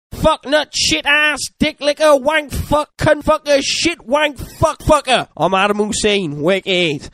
Swearing.mp3